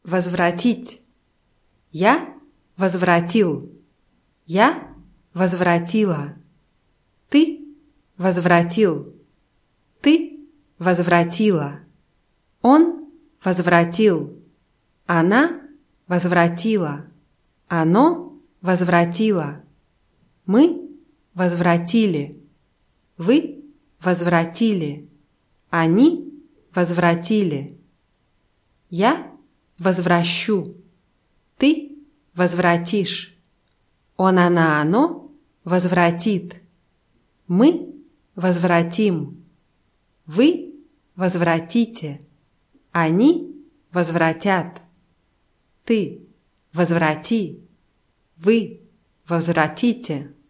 возвратить [wazwratʲítʲ]